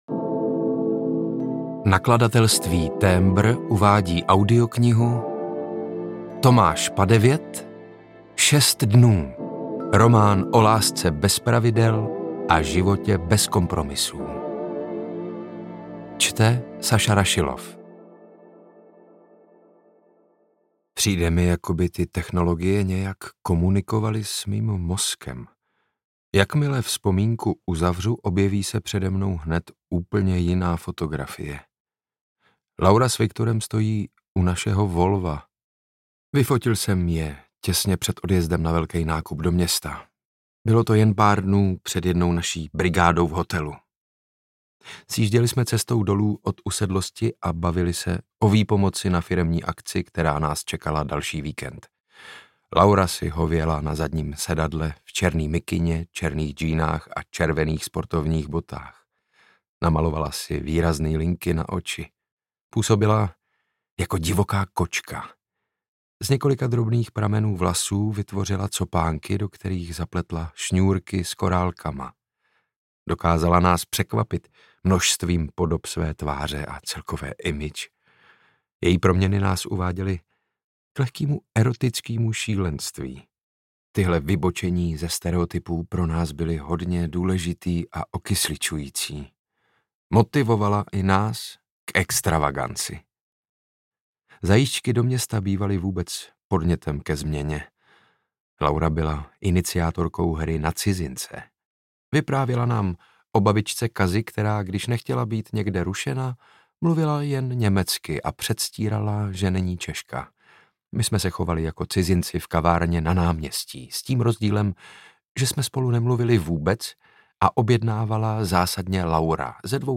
Šest dnů audiokniha
Ukázka z knihy
• InterpretSaša Rašilov